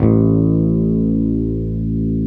Index of /90_sSampleCDs/Roland - Rhythm Section/BS _Jazz Bass/BS _Jazz Basses